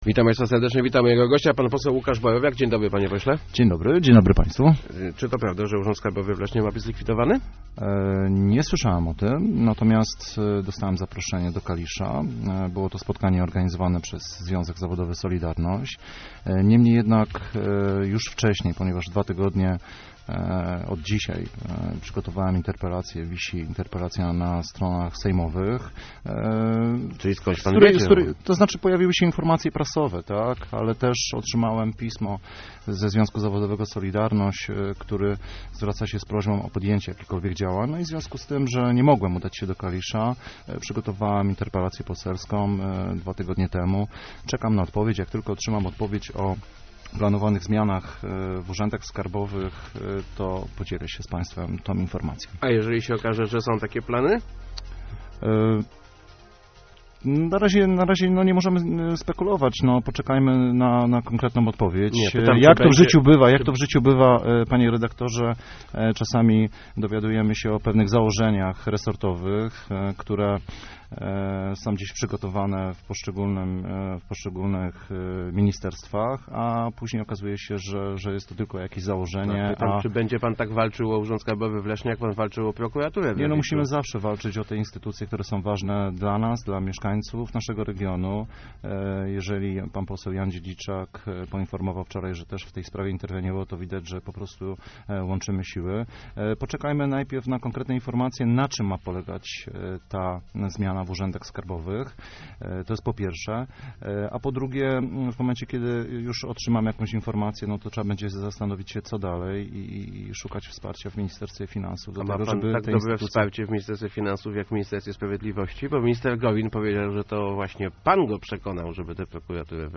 Miejska i powiatowa struktura leszczyńskiej PO zostały rozdzielone. To oddolna inicjatywa członków koła miejskiego - powiedział w Rozmowach Elki poseł Łukasz Borowiak.